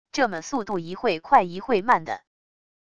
这么速度一会快一会慢的wav音频